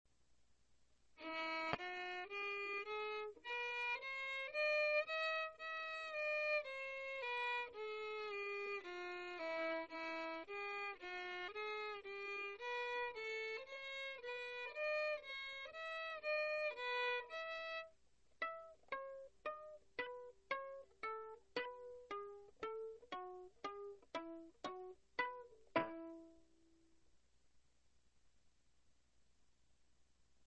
E-Dur
(Mikrofon auf Geige gelegt)
legato,
in Terzen,
pizzicato)